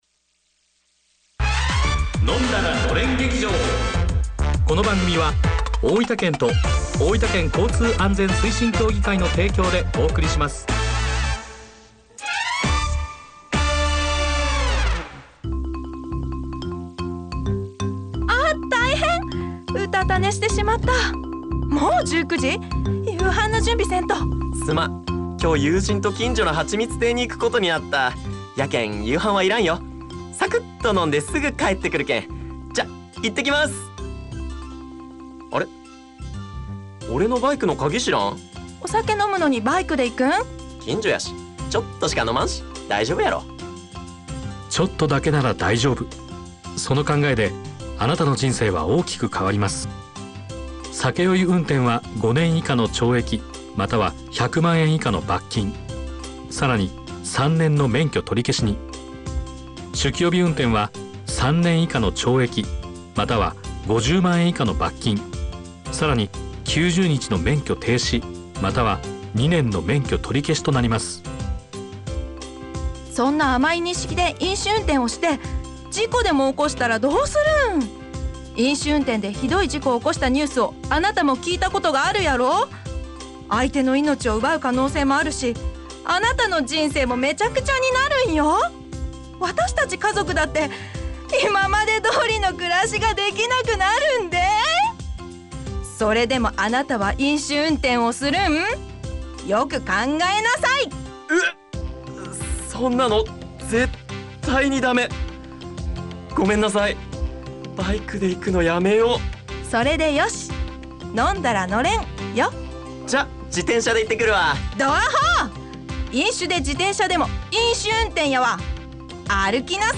ラジオドラマ「飲んだらのれん劇場」をFM大分にて放送しました。